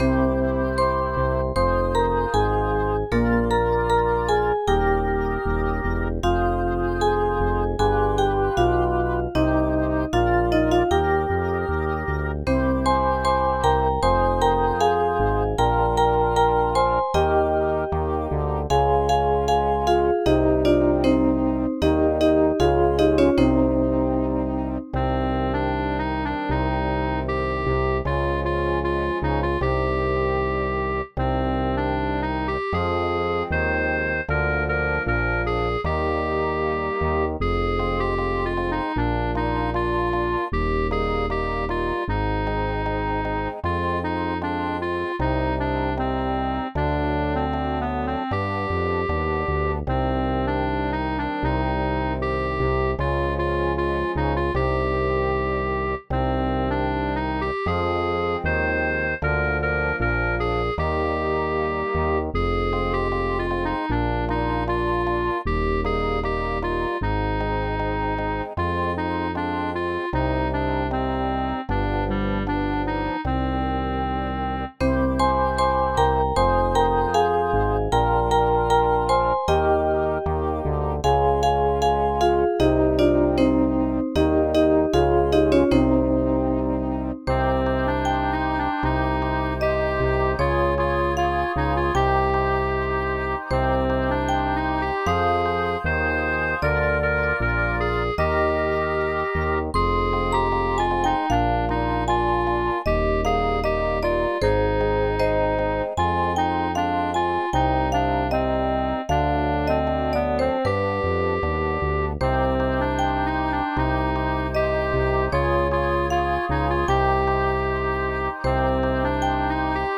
СТИЛЬОВІ ЖАНРИ: Ліричний
ВИД ТВОРУ: Авторська пісня